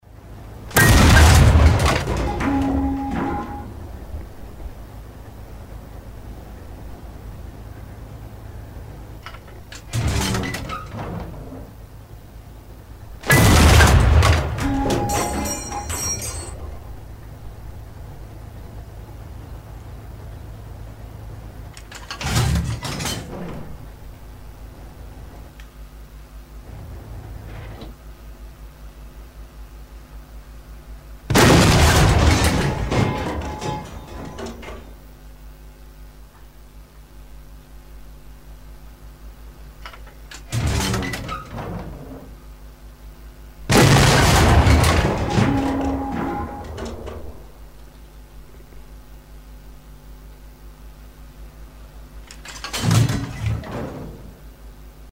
Вы можете слушать онлайн или скачать эффекты в высоком качестве: от глухих залпов тяжелых пушек до резких выстрелов легкой артиллерии.
122 mm Soviet Union